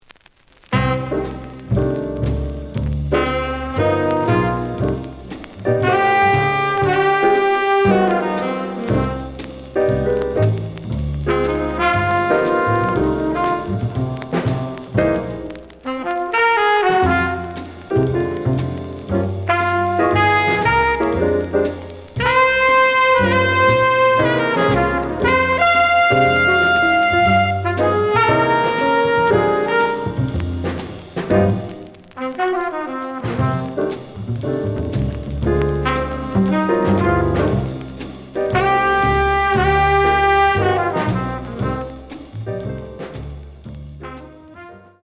The audio sample is recorded from the actual item.
●Genre: Hard Bop